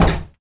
用地毯盖的木台阶。手滑过栏杆。脚跟鞋。石房子在荷兰。建立1967.Microphone在楼梯顶部。 齿轮链：Rode Blimp中的Rode NT4 XY和Rode NTG1＆gt;声音设备302＆gt; Tascam dr100 Mk2。
标签： 地毯 楼梯 楼梯 攀岩 木楼梯 台阶 上楼梯 脚步 行走 木材 楼梯 脚步声 走路
声道立体声